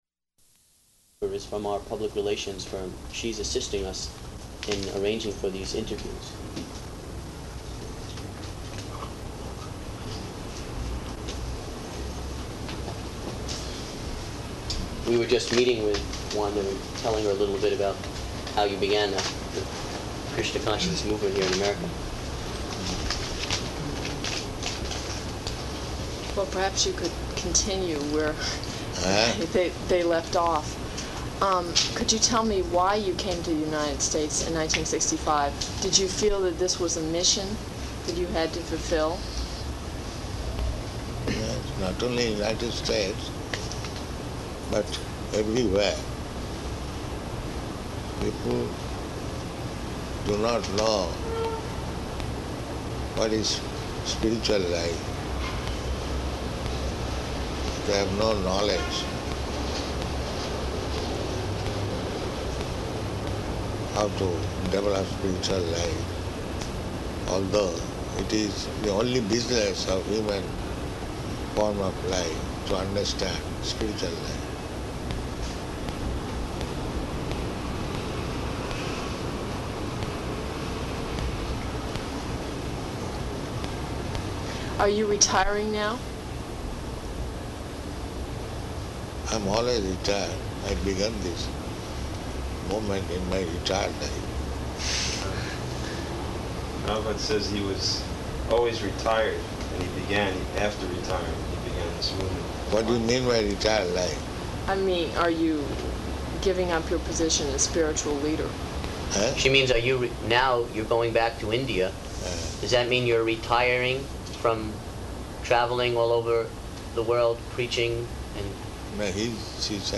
Interview with Newsweek
-- Type: Interview Dated: July 14th 1976 Location: New York Audio file